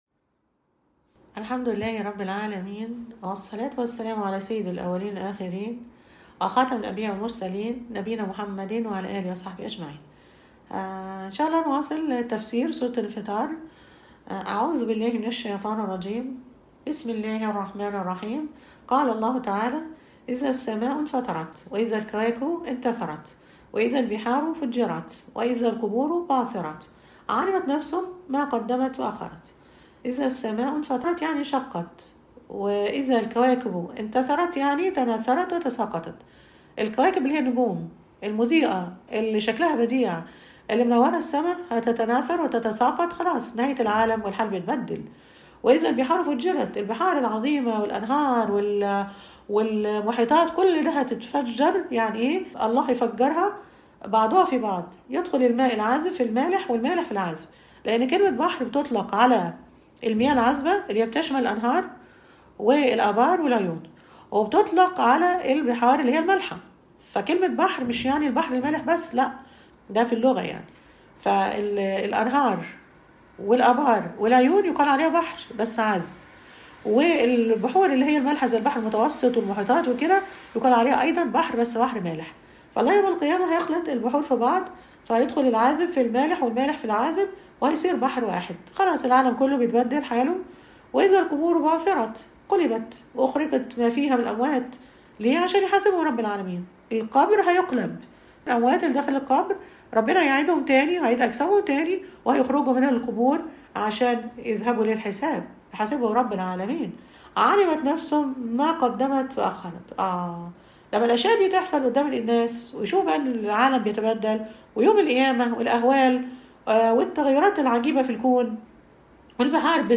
المحاضرة السادسة_ سورة الإنفطار